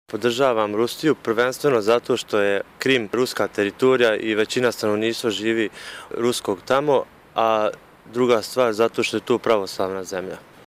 Pitali smo građane na ulicama gradova Crne Gore, Srbije i Bosne i Hercegovine šta misle o krizi u Ukrajini.